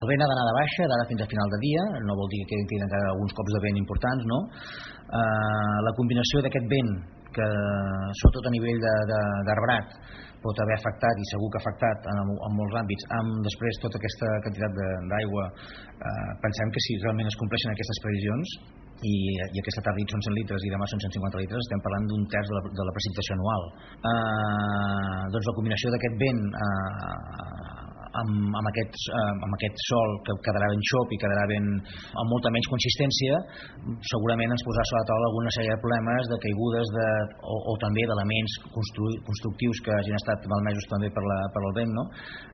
En parla l’alcalde de Palafolls Francesc Alemany.